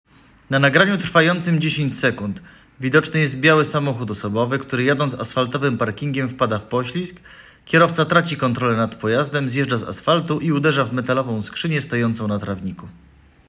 Nagranie audio audiodeskrypcja_do_filmu_-_drifty_na_myslenickim_zarabiu1.m4a